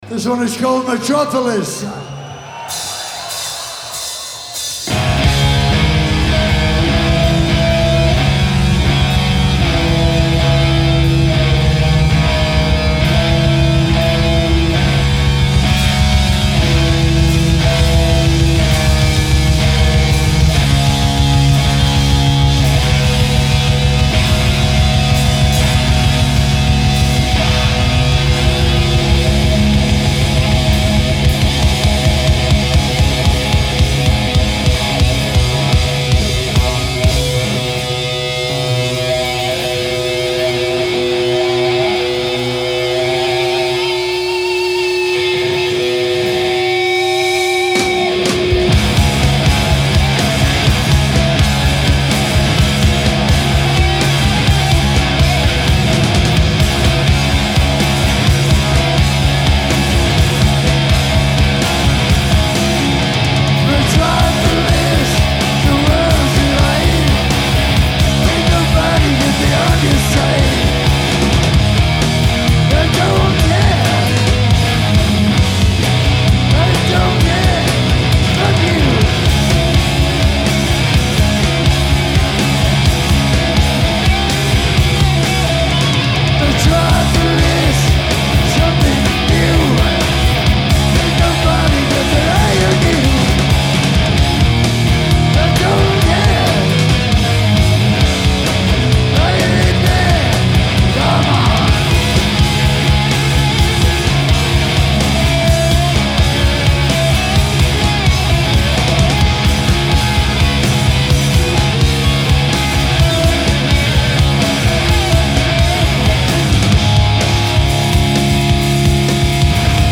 Hi-Res Stereo
Genre : Rock